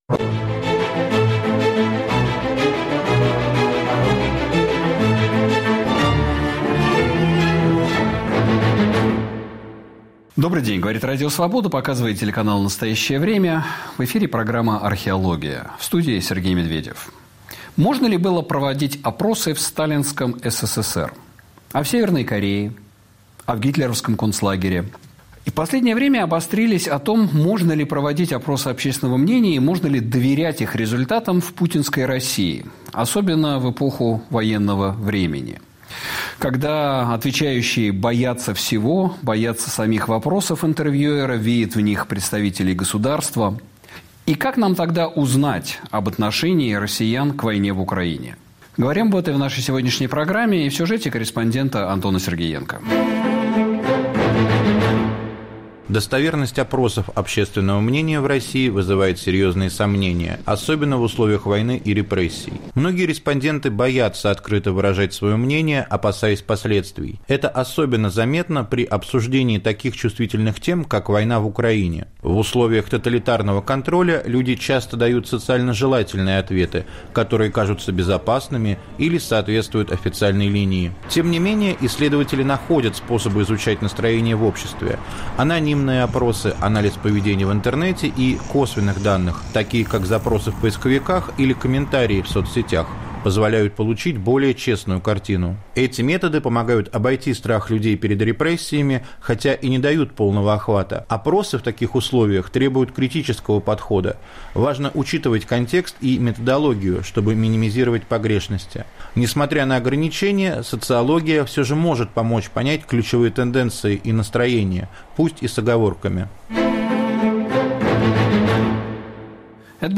Повтор эфира от 29 января 2025 года.